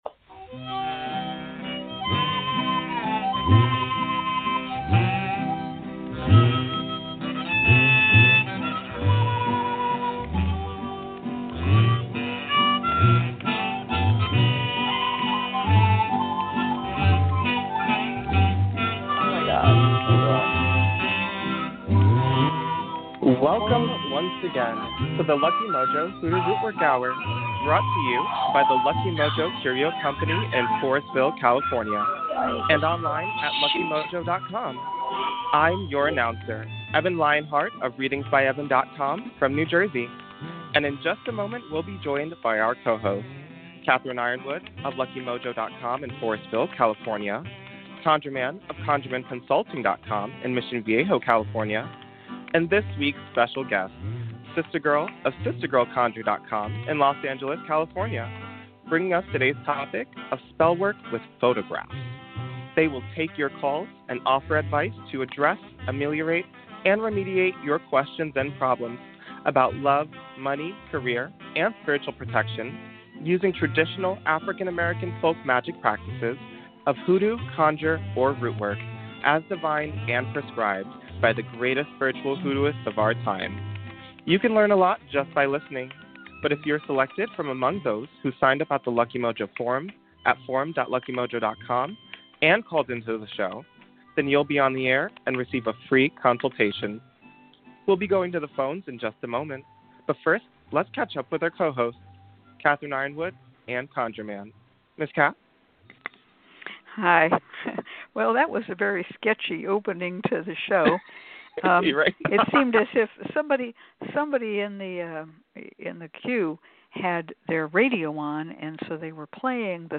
We begin this show with a Discussion Panel focussed on the topic of Spellwork with Photographs.